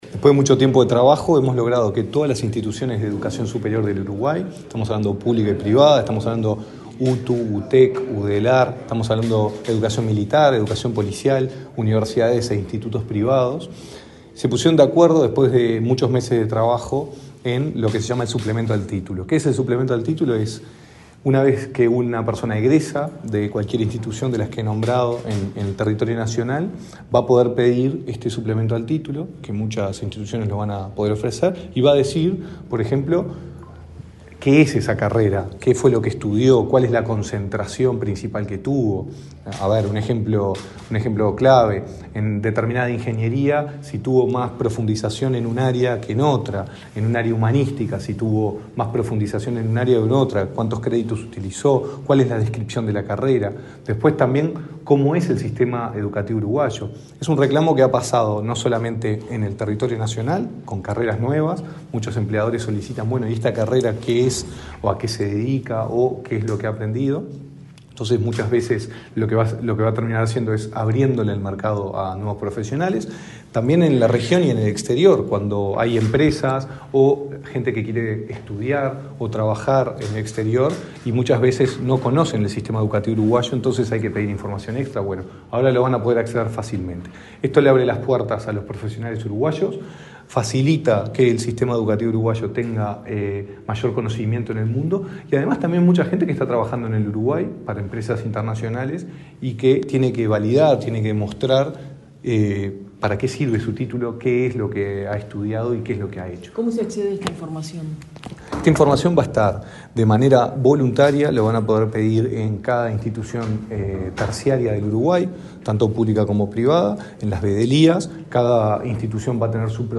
Declaraciones del director nacional de Educación, Gonzalo Baroni
Este lunes 26 en el Ministerio de Educación y Cultura, el director nacional de Educación, Gonzalo Baroni, dialogó con la prensa, acerca del